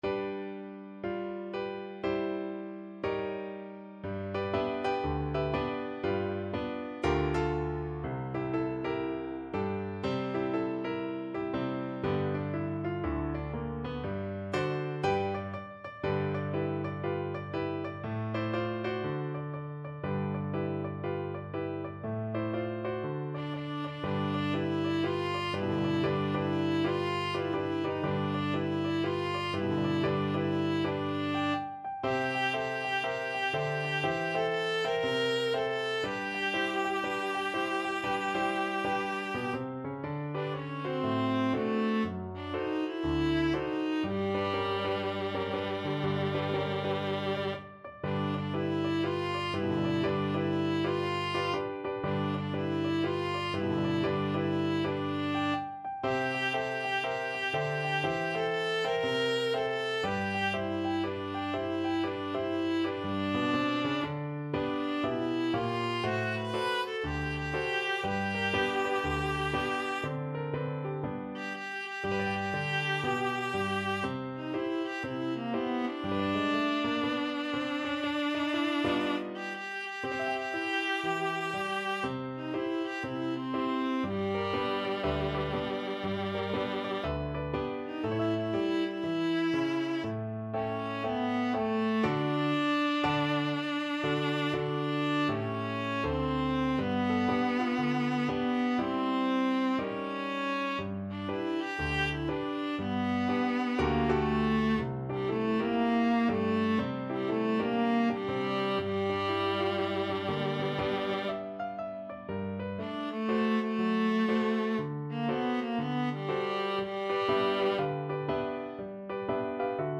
2/4 (View more 2/4 Music)
Jazz (View more Jazz Viola Music)